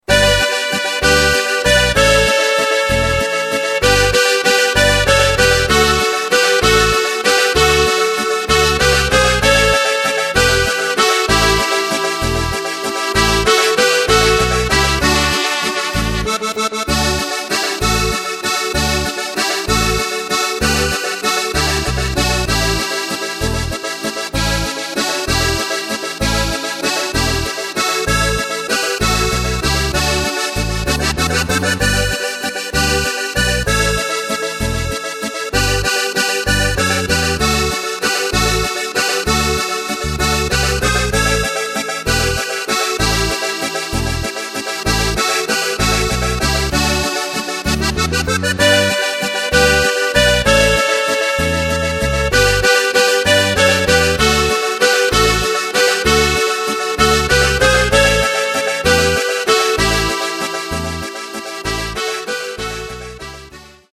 Takt:          3/4
Tempo:         193.00
Tonart:            D
Walzer (Volklied) aus dem Jahr 2008!
Playback mp3 Demo